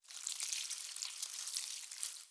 SFX_WateringPlants_02_Reverb.wav